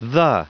Prononciation du mot the en anglais (fichier audio)
Prononciation du mot : the